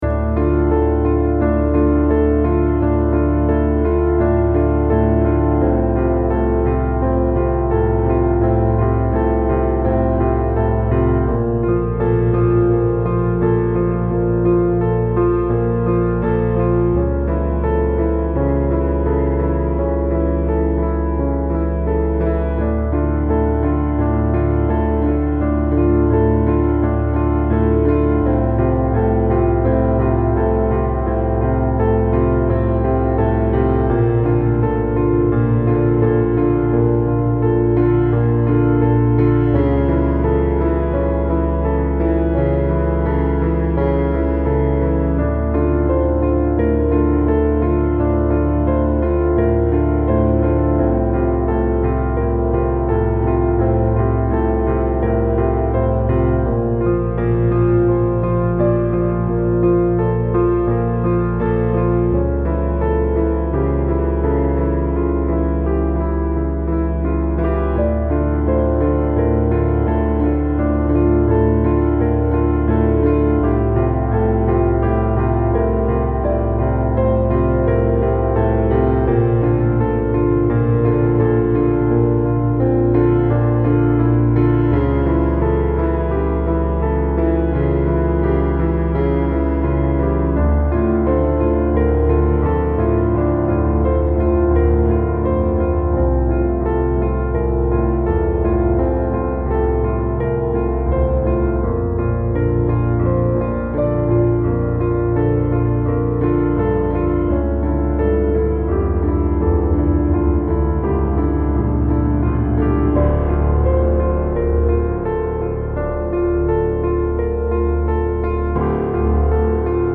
Style Style Soundtrack
Mood Mood Dark
Featured Featured Piano, Synth
BPM BPM 85